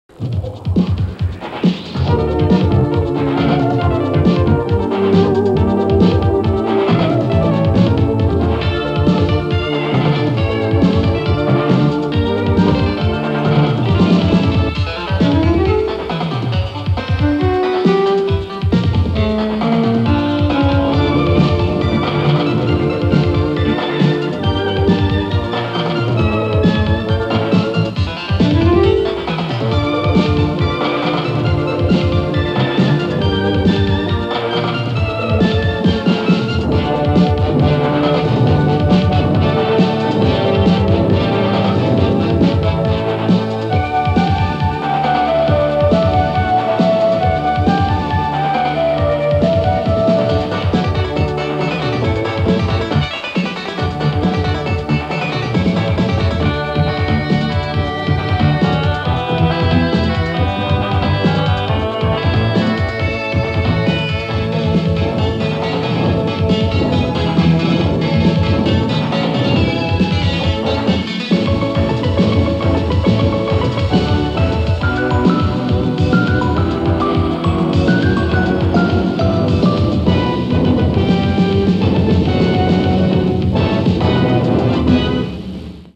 Opening theme (audio) .wma 1.33 MB